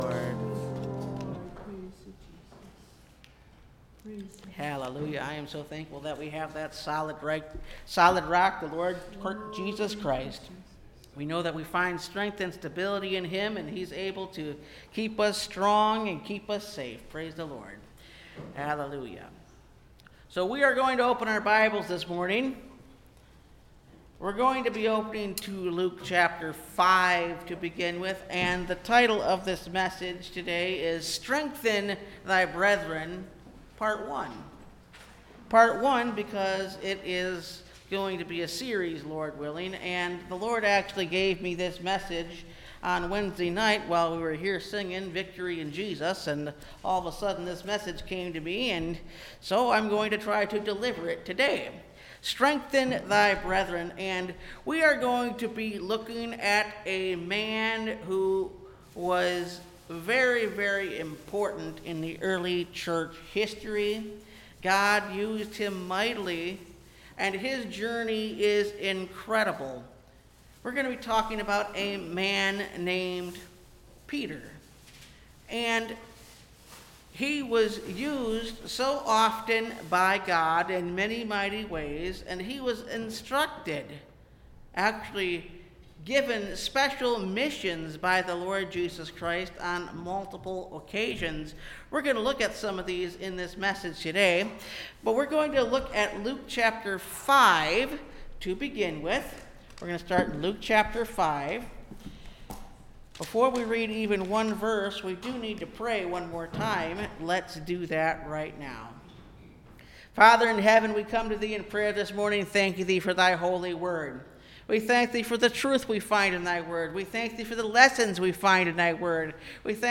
Strengthen Thy Brethren (Part 1) (Message Audio) – Last Trumpet Ministries – Truth Tabernacle – Sermon Library